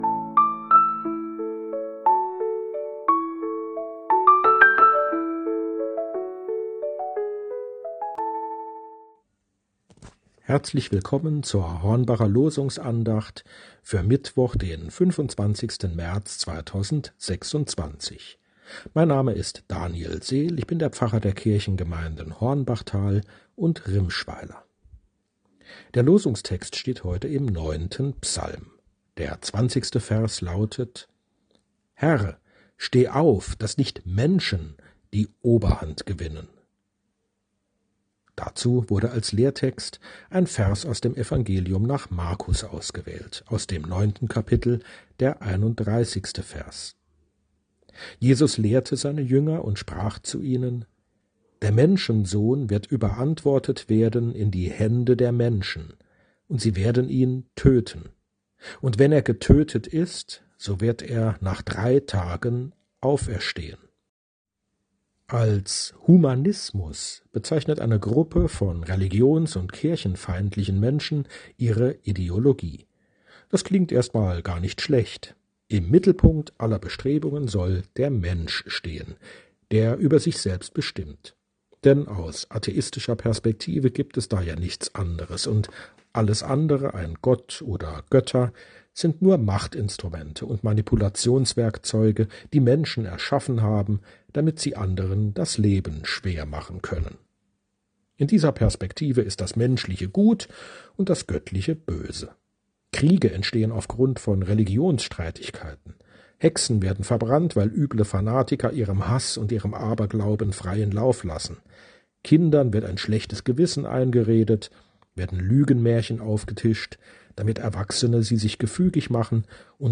Losungsandacht für Mittwoch, 25.03.2026